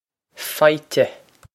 Faighte Fie-teh
Pronunciation for how to say
This is an approximate phonetic pronunciation of the phrase.